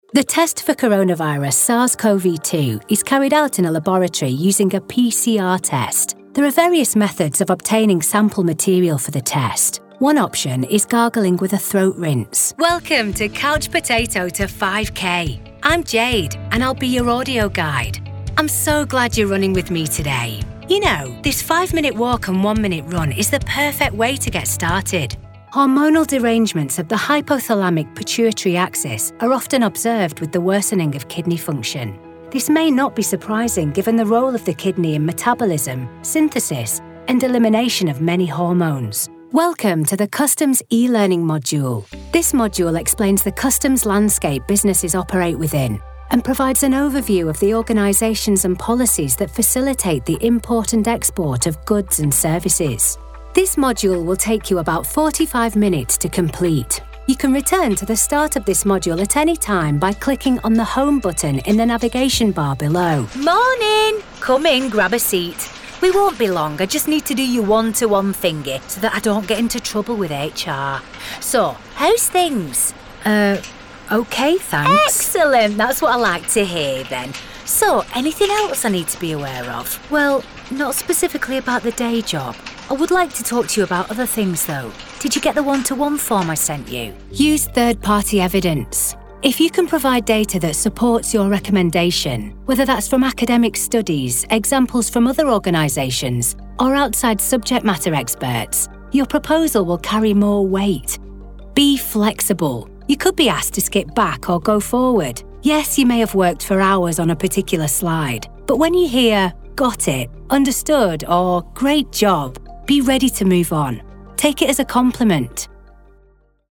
Anglais (Britannique)
Naturelle, Distinctive, Polyvalente, Amicale, Chaude
E-learning
A naturally northern voice that CONNECTS with warmth, depth and authenticity. Dial-down to soft, neutral northern or dial-up to broad Lancashire/Manchester.